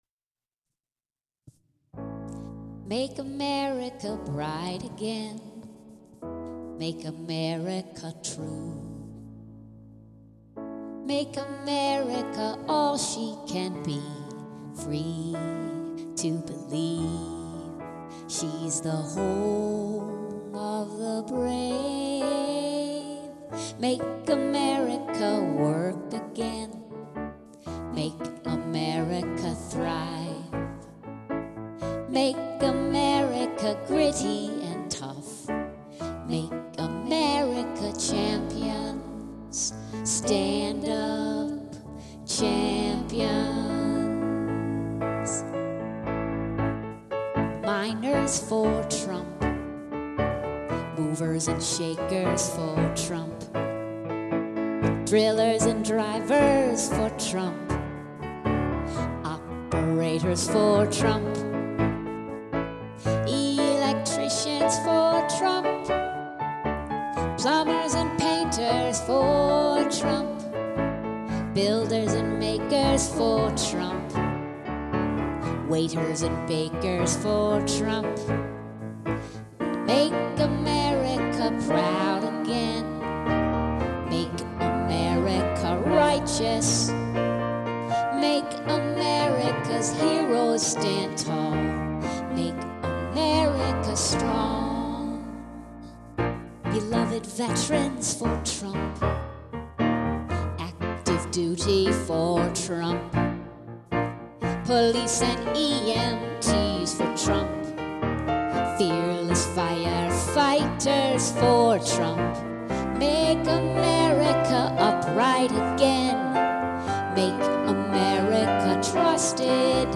A great song, very professional, very moving.
What a beautiful, warm, inspiring, unapologetically America strong, America first song.
It makes me think of some of the music of WWII which was so uplifting and encouraging.